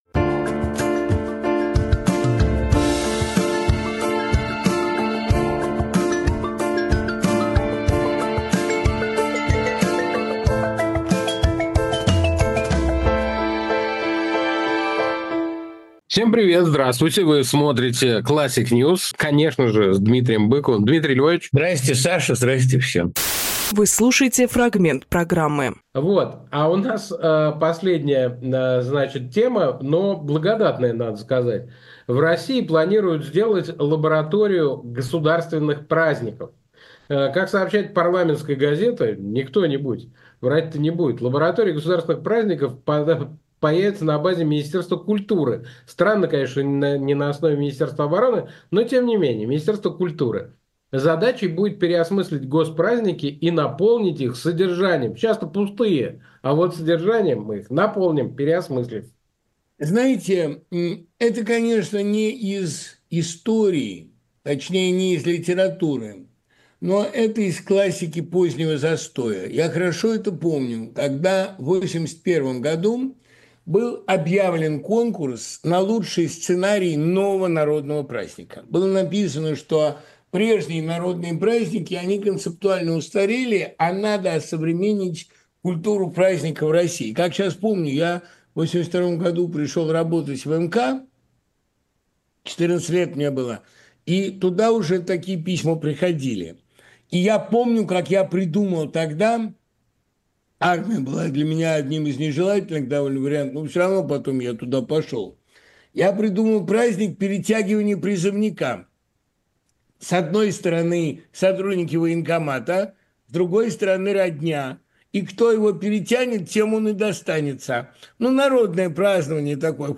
Фрагмент эфира от 25 ноября.